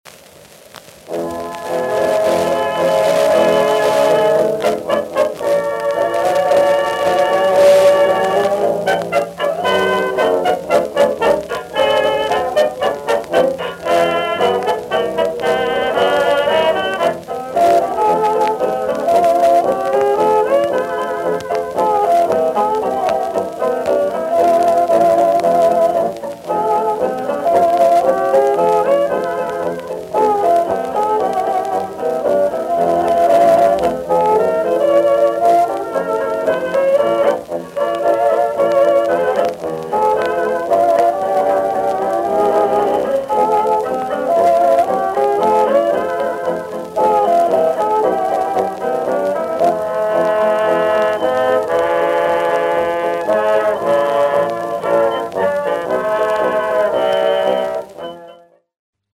RIAA, De-Click 3.0Mil